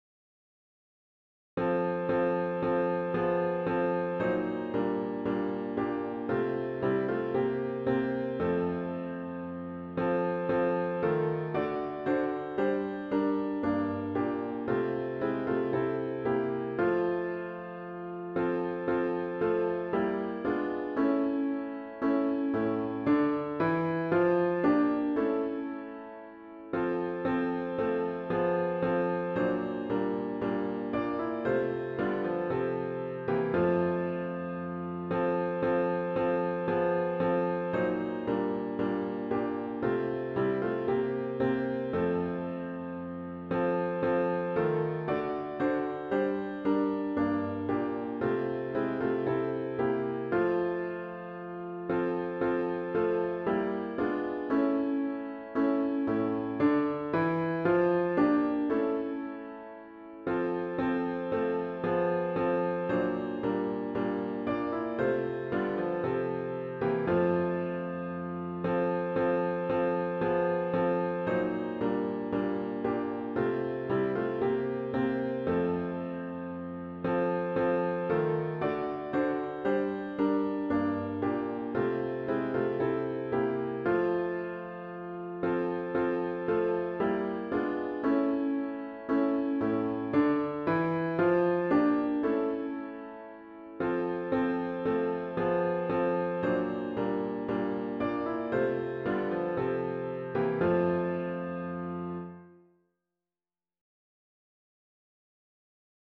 HYMN   “O Little Town of Bethlehem”   GtG 121   (Verses 1, 3, & 4)